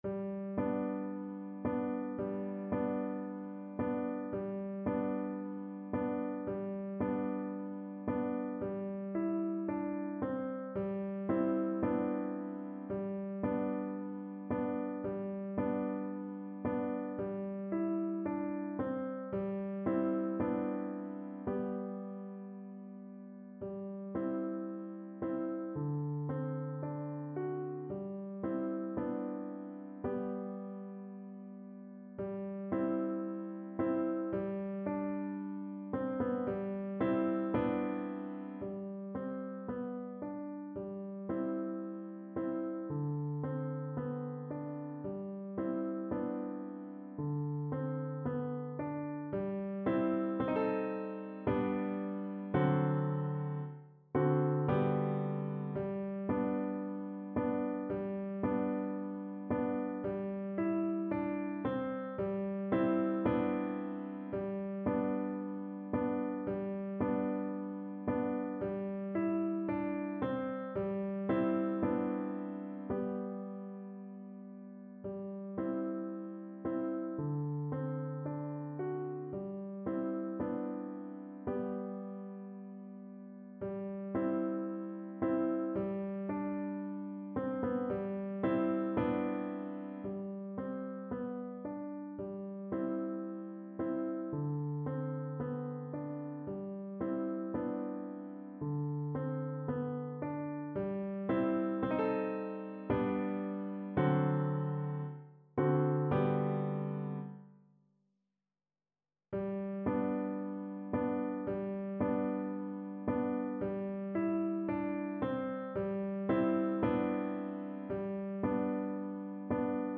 kolęda: Gdy śliczna panna (na wiolonczelę i fortepian)
Symulacja akompaniamentu